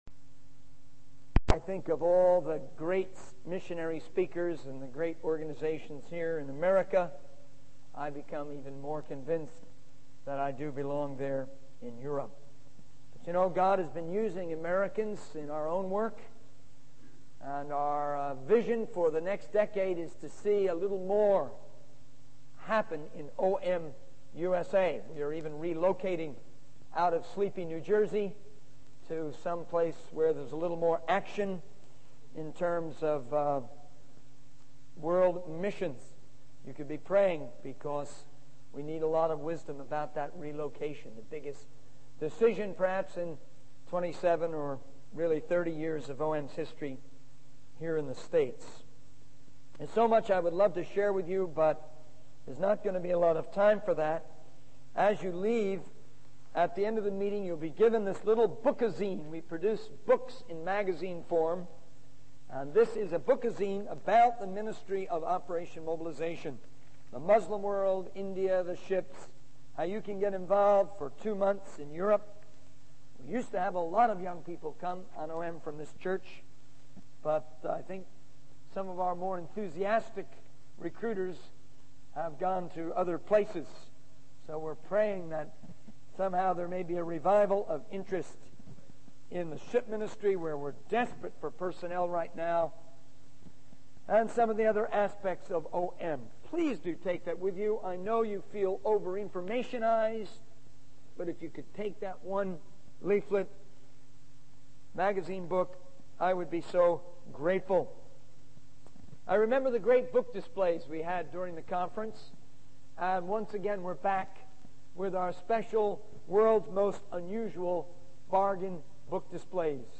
In this sermon, the speaker emphasizes the importance of whole-hearted commitment to Jesus Christ. He encourages the audience to be doers of the Word and not just hearers, using the analogy of a man who forgets his own reflection after looking in a mirror.